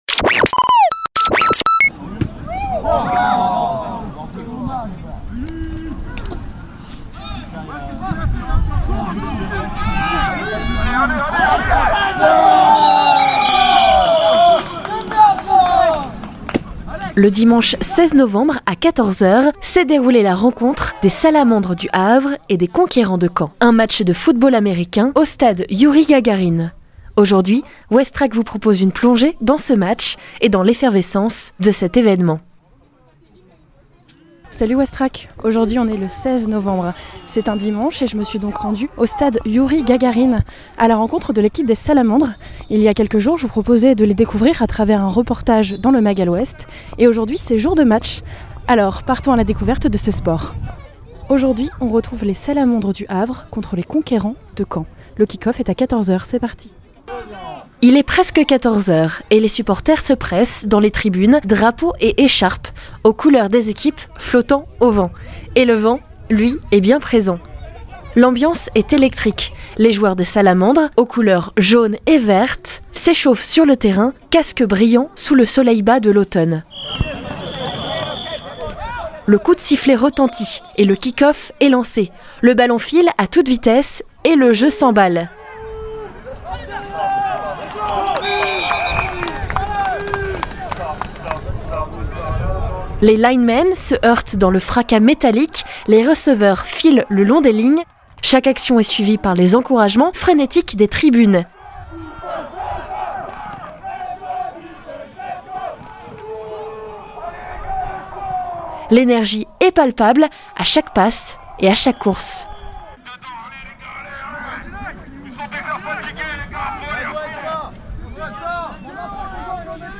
Reportage Match Novembre Les Salamandres.mp3